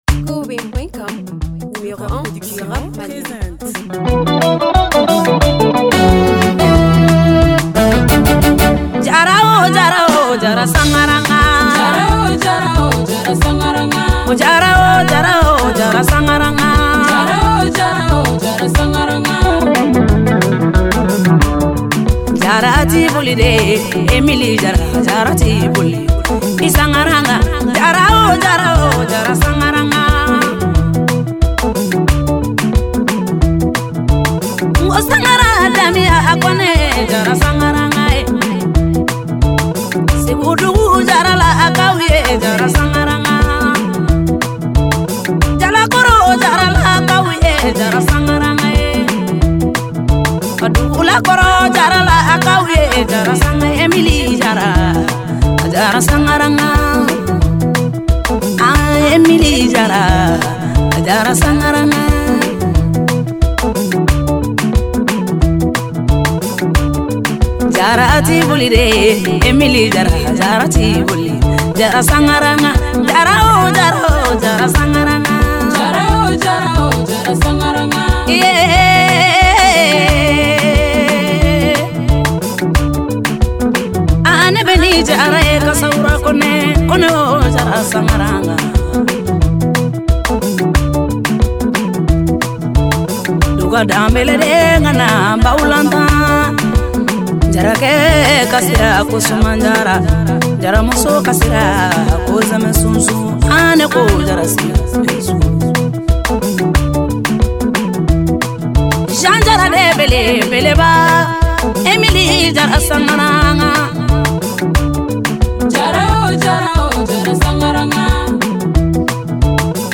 musique Mali world.